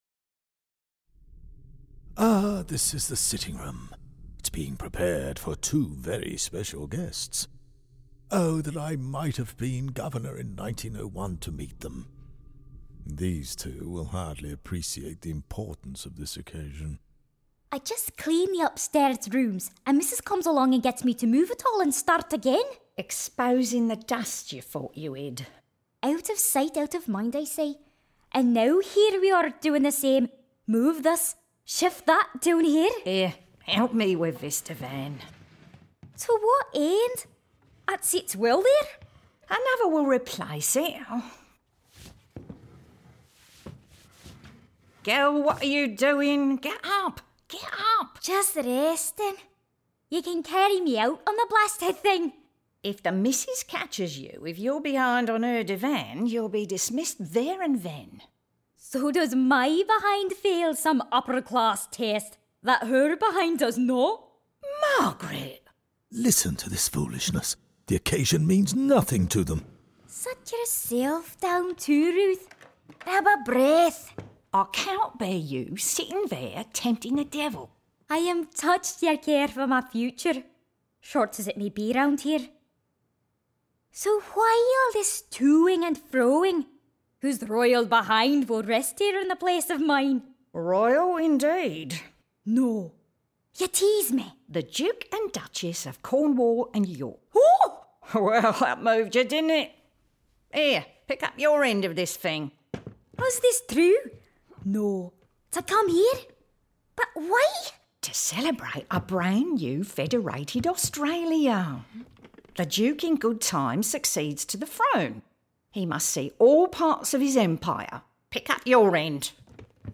Led by the ghost of Governor Musgrave, who died in the House, the podcast tour guides you through key moments in the life of the House with audio dramatisations of exchanges that would have occurred between the characters of 19th century Queensland.